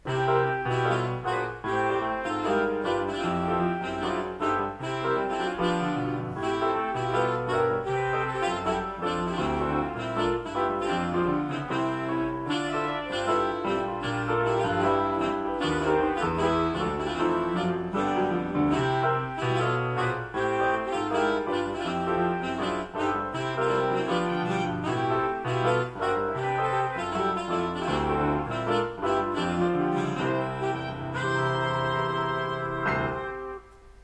הסימפוניה התשיעית של בטהובן (ג'אז)